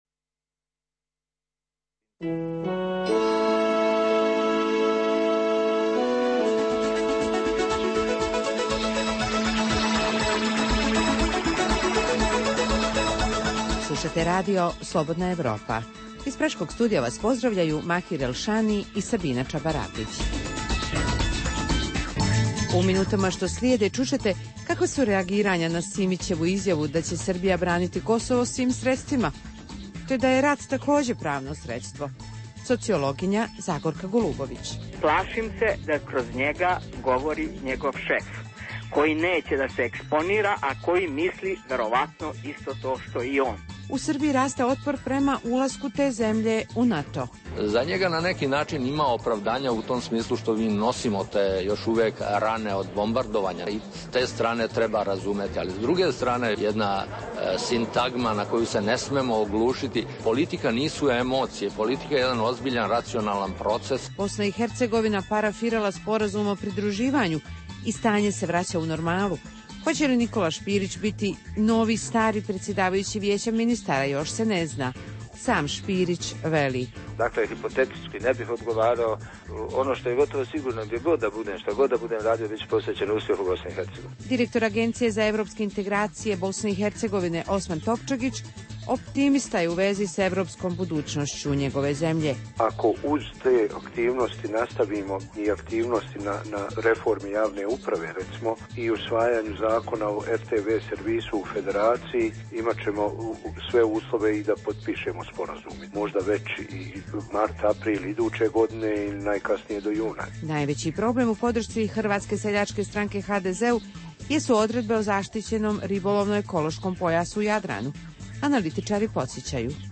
Preostalih pola sata emisije, nazvanih “Dokumenti dana” sadrže analitičke teme, intervjue i priče iz života.